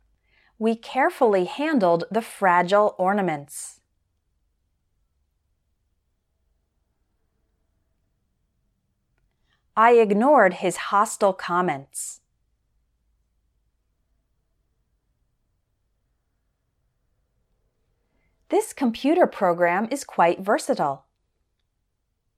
Now let’s work on words in which the -ILE ending is pronounced “ull” as in mobile.